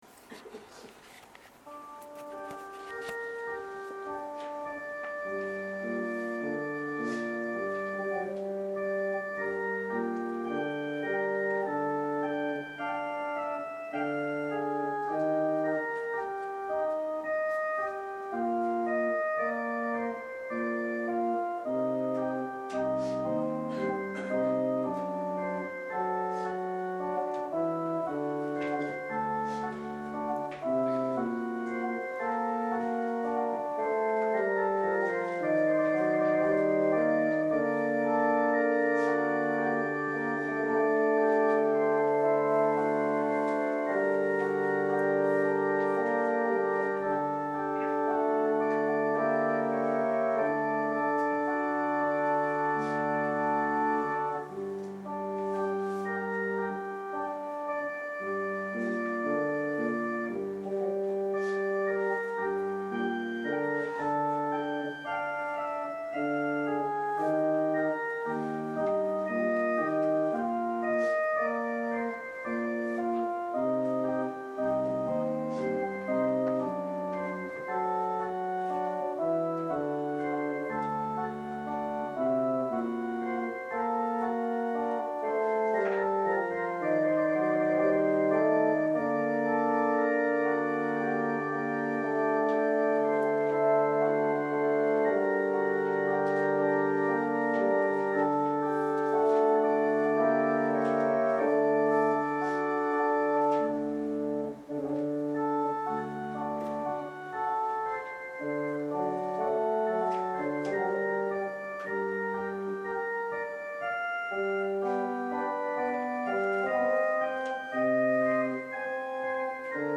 聖日礼拝のご案内（待降節第 ２主日） – 日本基督教団 花小金井教会